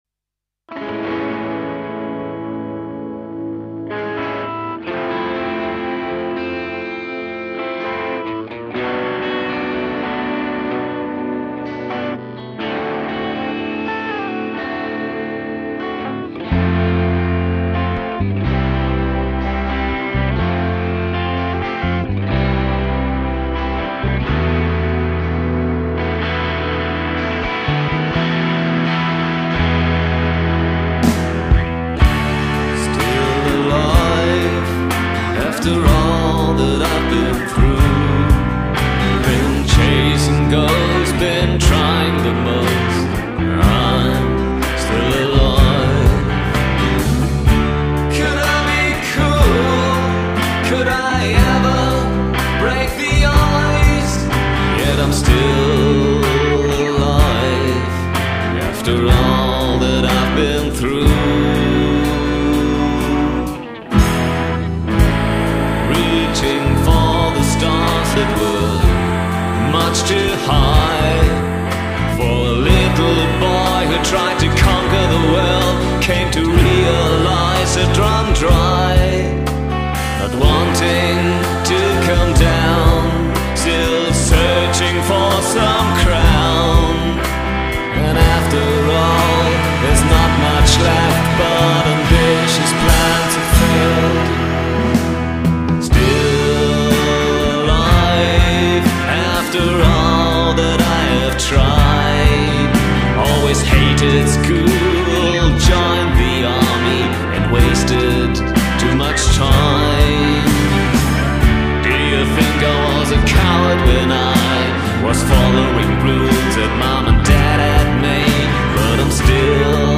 => elektrische Version, unveröffentlicht